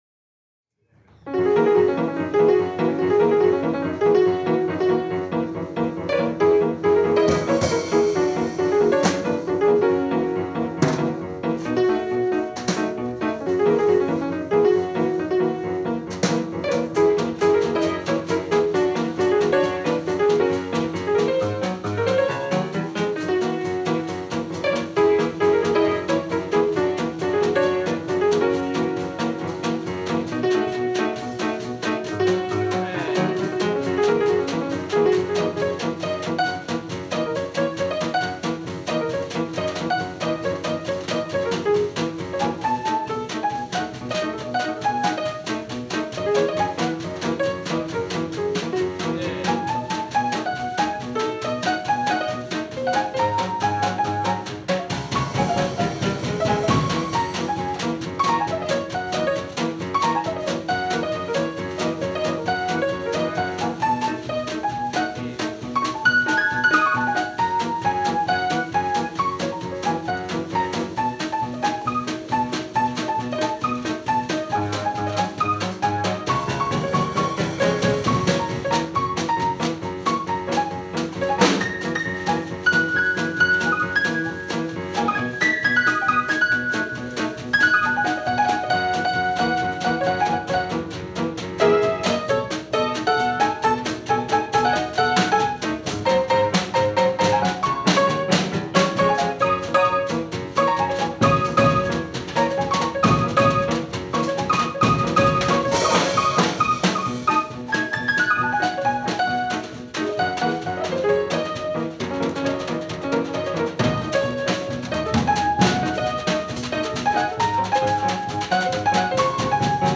free jazz and improvised music
piano
drums